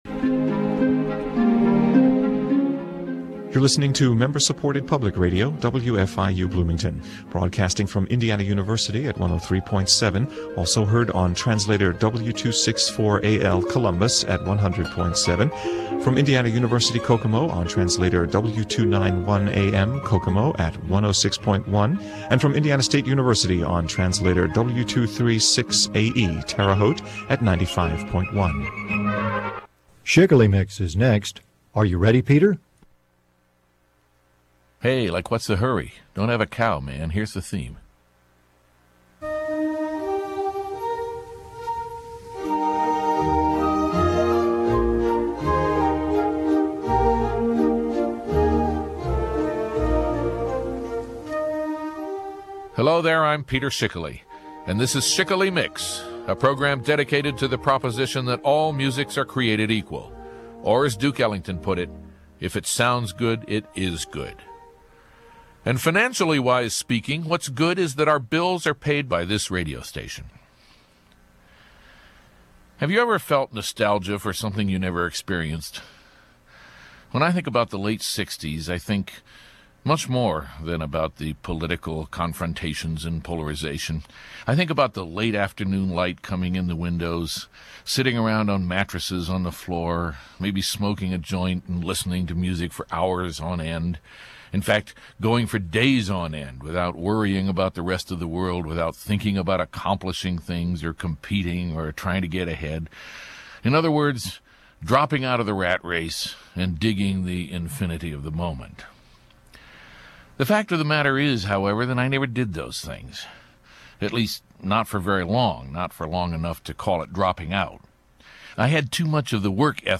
Schickele Mix is written & hosted by Peter Schickele, produced by Tom Voegeli Productions, and distributed nationwide by Public Radio International.